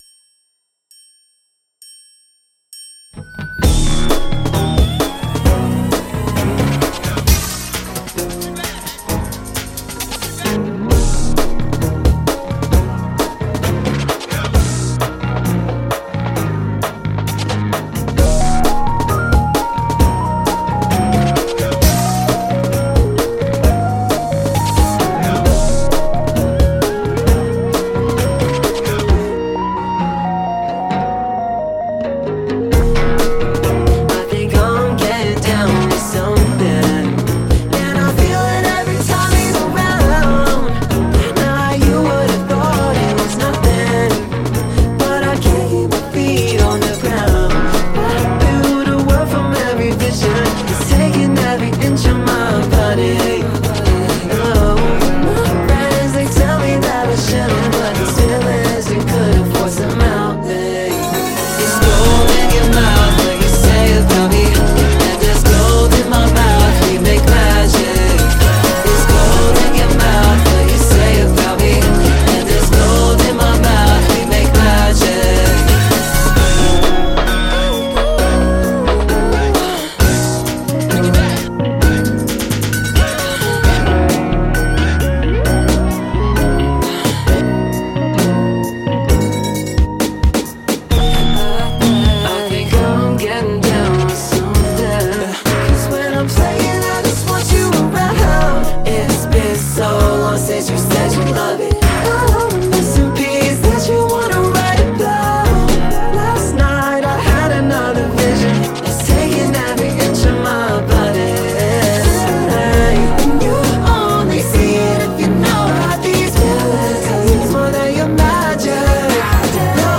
американский музыкальный продюсер.